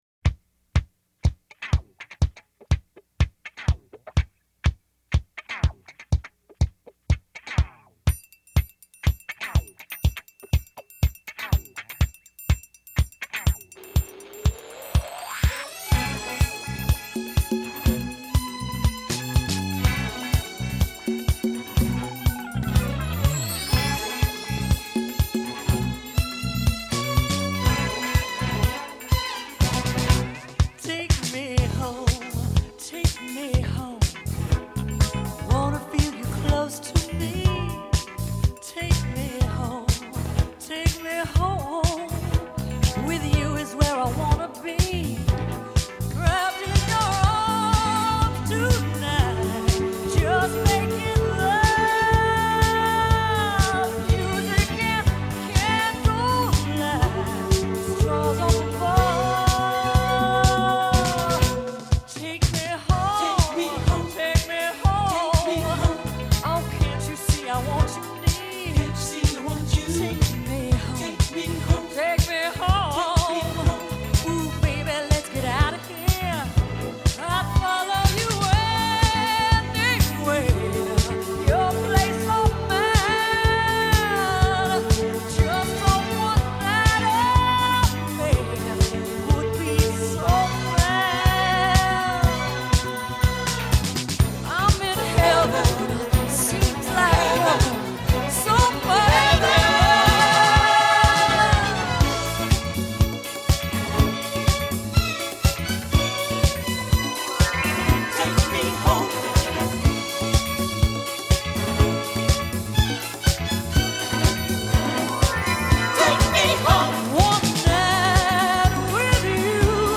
1979   Genre: Pop   Artist